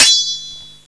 sword_shieldxx.WAV